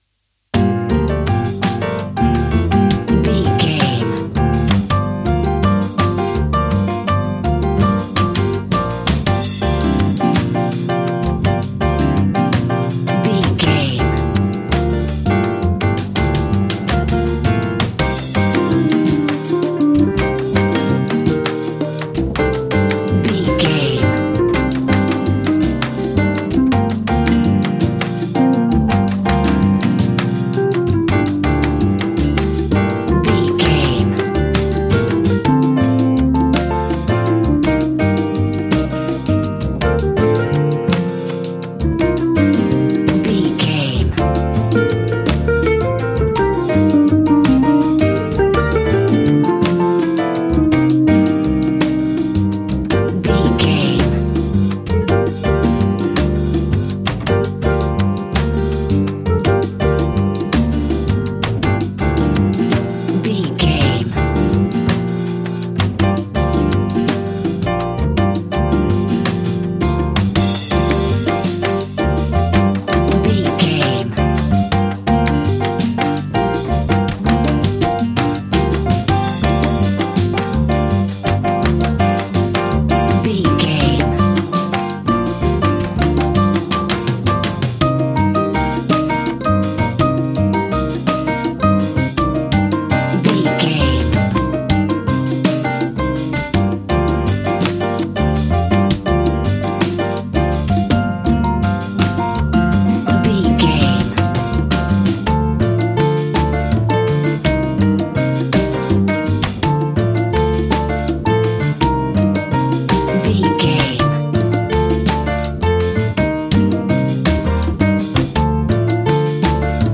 An exotic and colorful piece of Espanic and Latin music.
Aeolian/Minor
funky
energetic
romantic
percussion
electric guitar
acoustic guitar